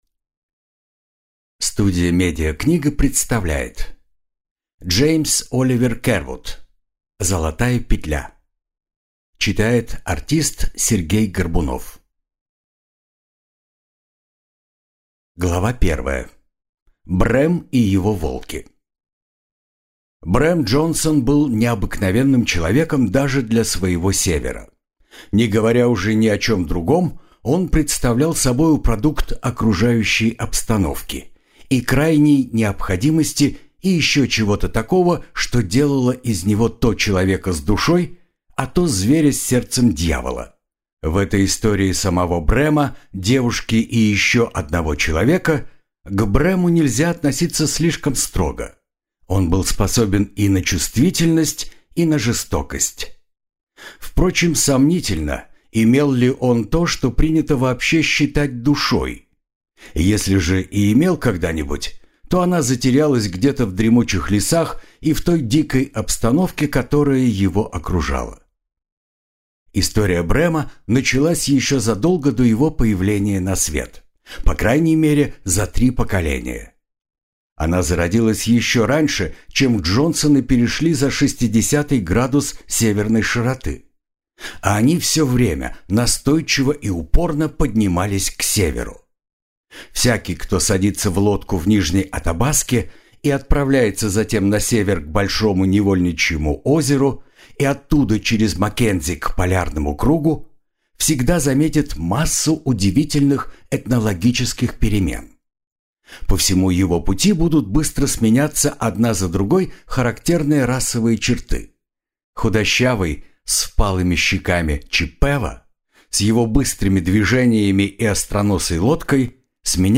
Аудиокнига Золотая петля | Библиотека аудиокниг